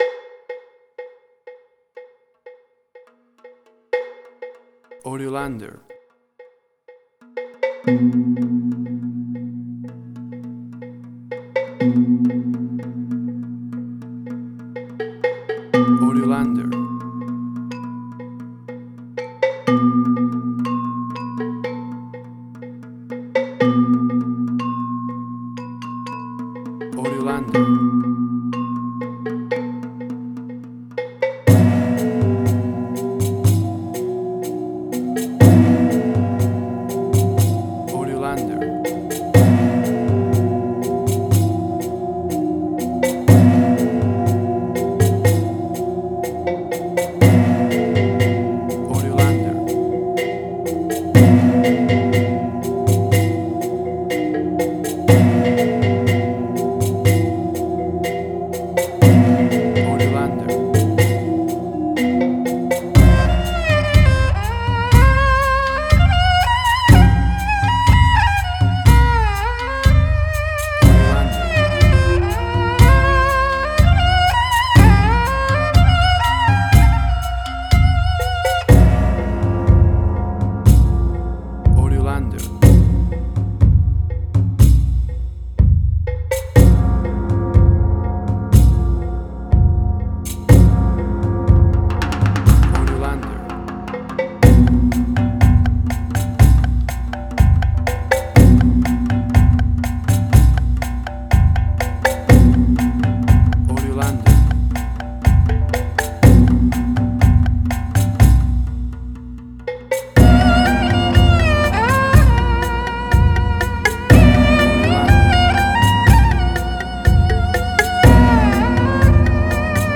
Chinese Action.
Tempo (BPM): 122